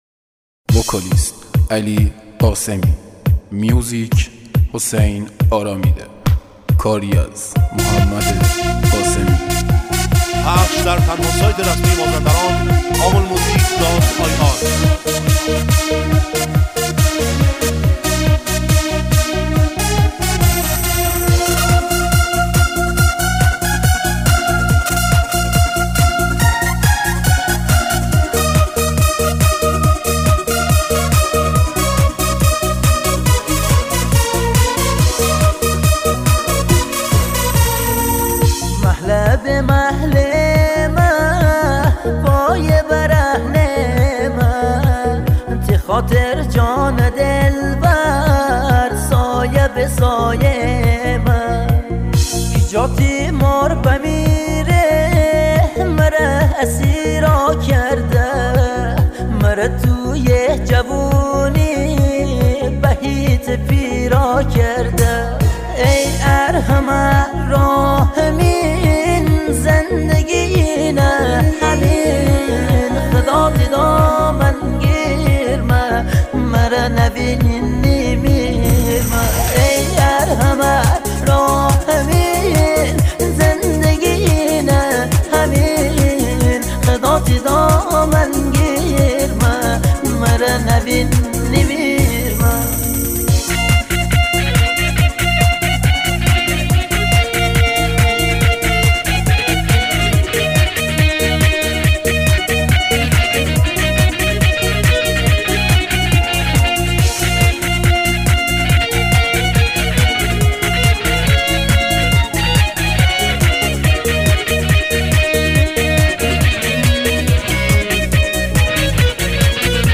آهنگ جدید مازندرانی
آهنگ شاد